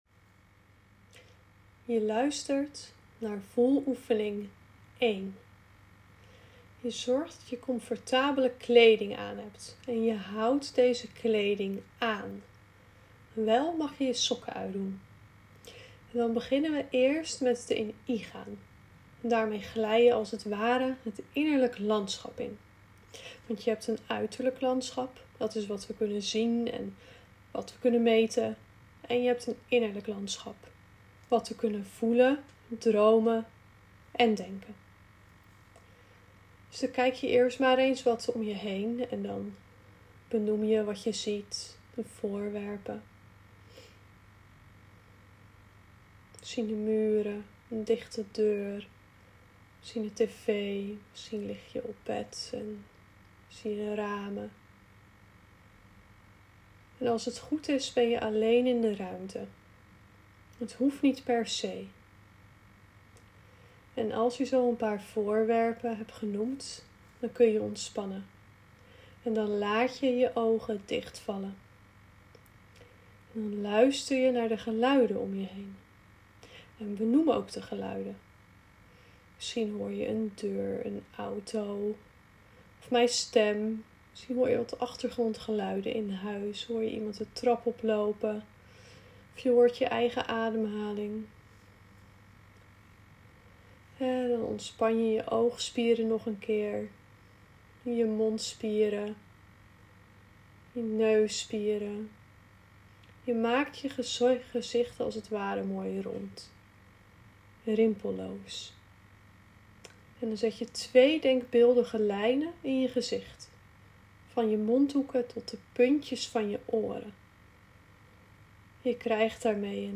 M01 Meditatie I Kracht